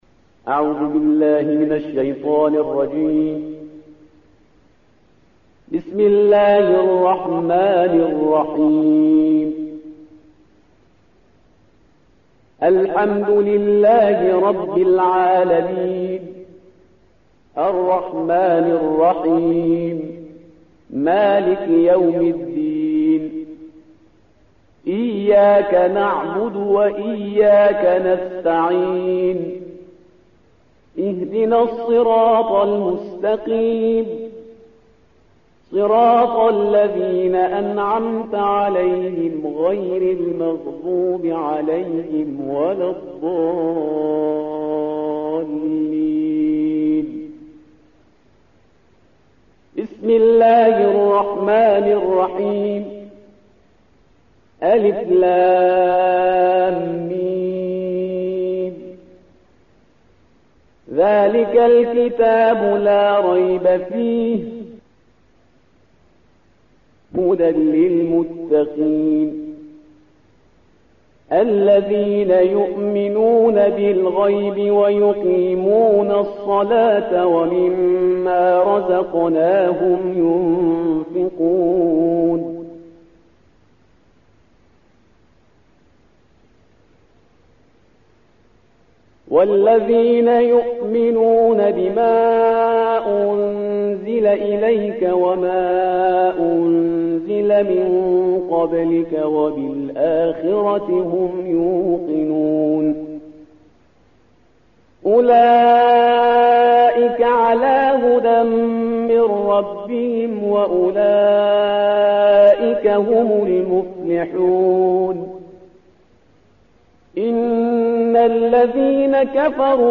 صوت/ ترتیل جزء اول قرآن کریم توسط استاد پرهیزگار